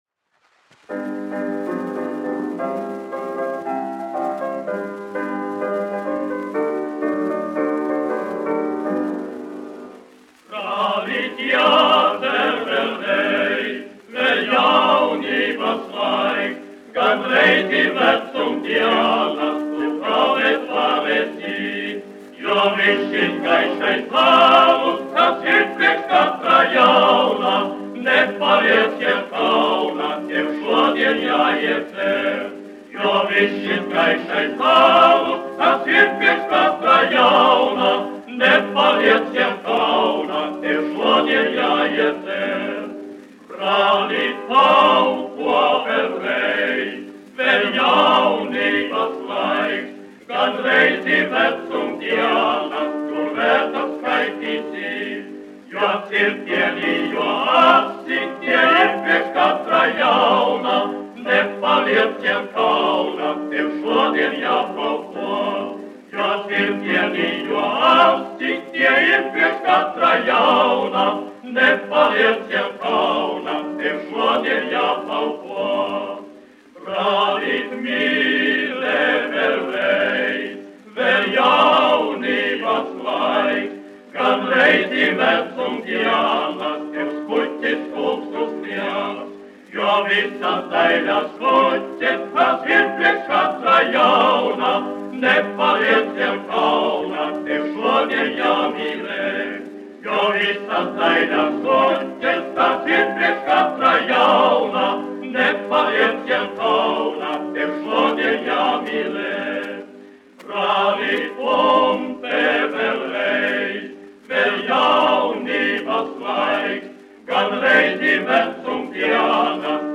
1 skpl. : analogs, 78 apgr/min, mono ; 25 cm
Vokālie seksteti
Skaņuplate